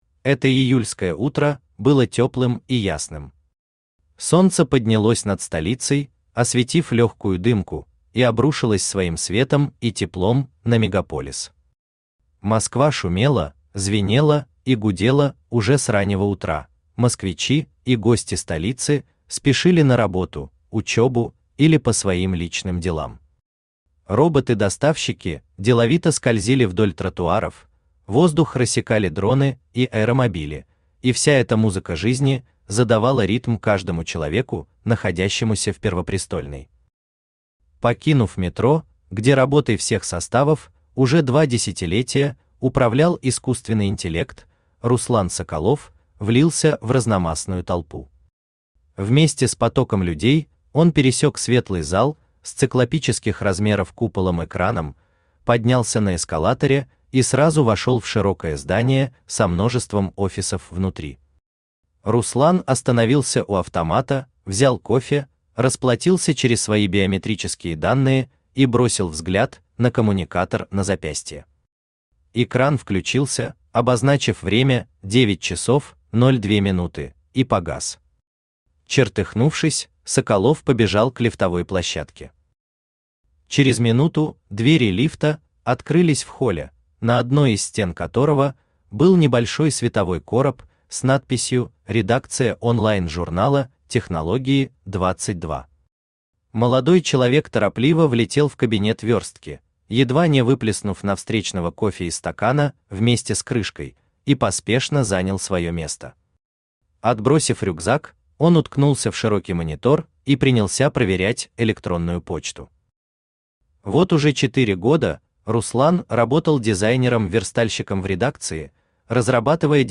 Аудиокнига Москва.Сити-2050 | Библиотека аудиокниг
Aудиокнига Москва.Сити-2050 Автор Наталья Эзер Читает аудиокнигу Авточтец ЛитРес. Прослушать и бесплатно скачать фрагмент аудиокниги